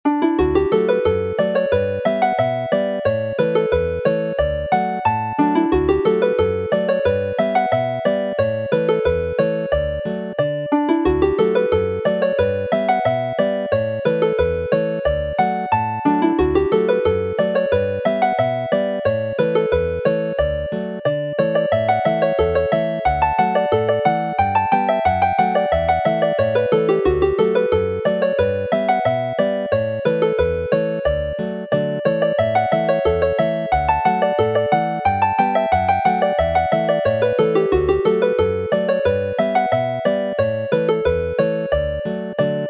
Play the reel / polka